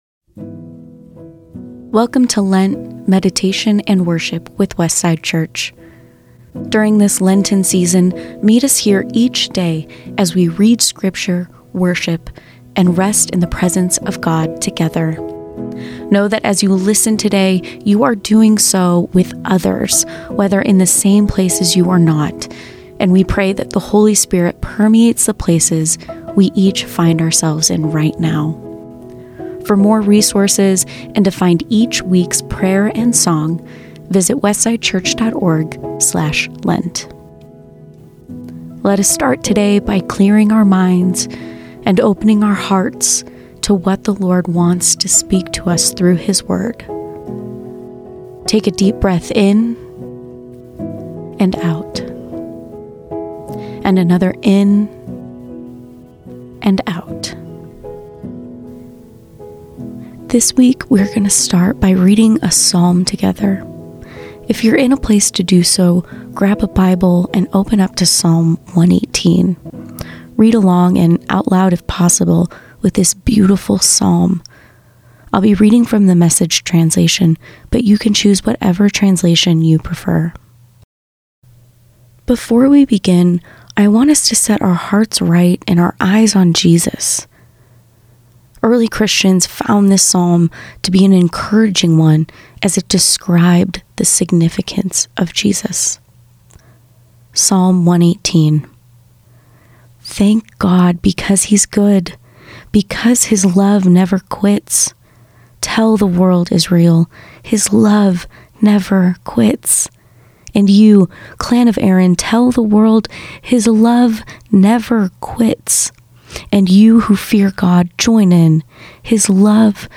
A reading from Psalm 118